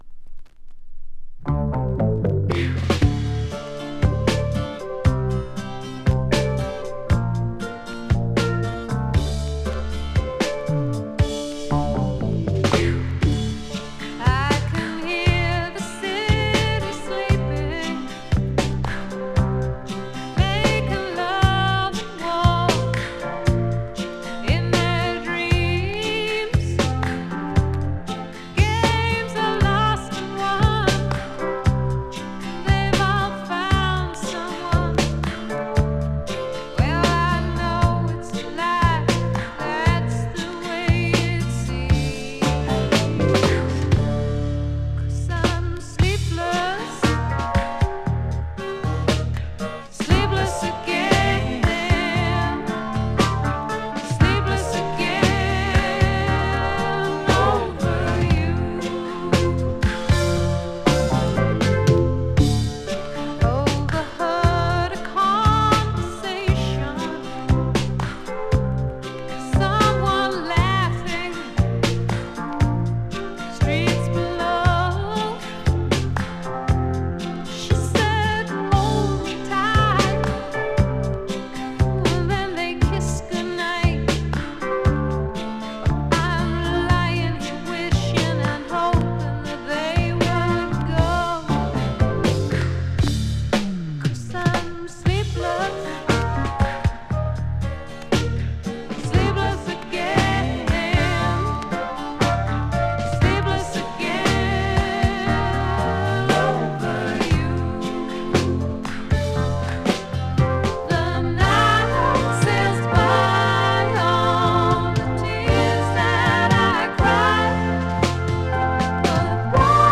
2. > ROCK/POP